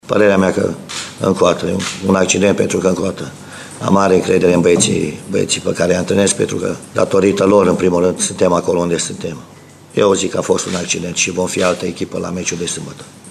În schimb, Vasile Miriuță, antrenorul clujenilor, a spus că rezultatul a fost un accident și a promis că echipa va arăta altfel în campionat:
Miriuta-a-fost-un-accident.mp3